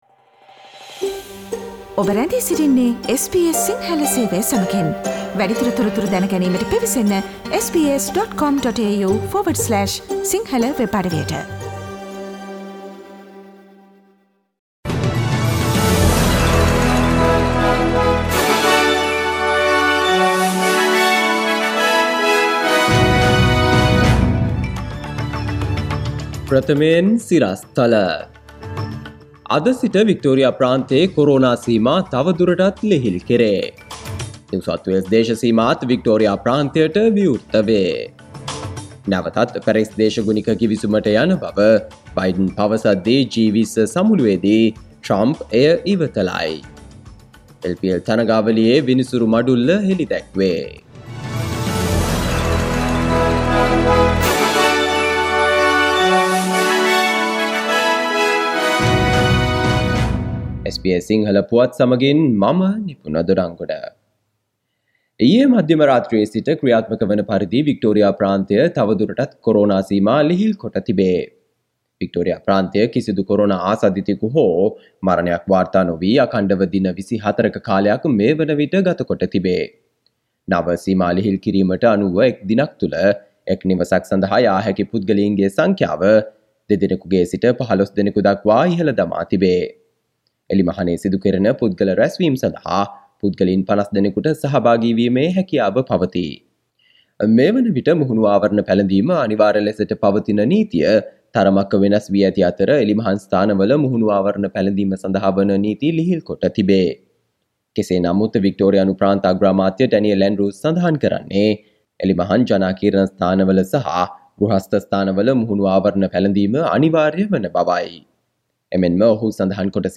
Today’s news bulletin of SBS Sinhala radio – Monday 23 November 2020.